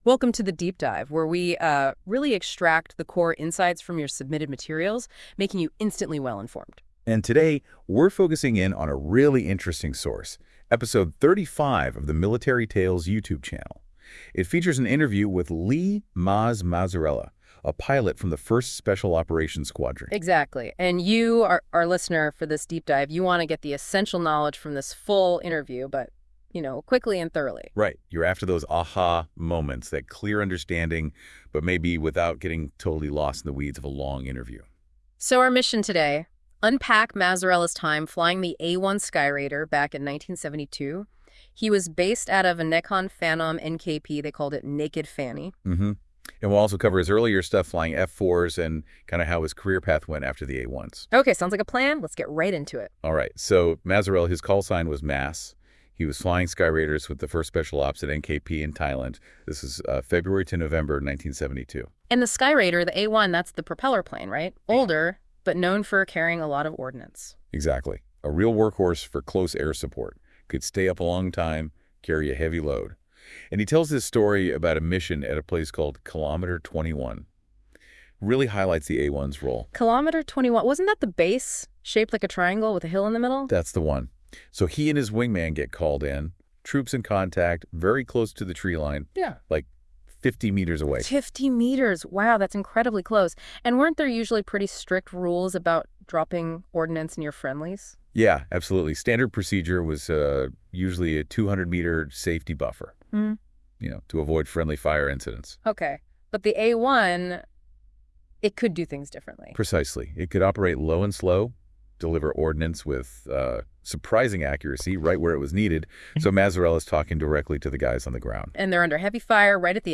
Full Interview - Military Tales